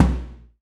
ORCH BD   -S.WAV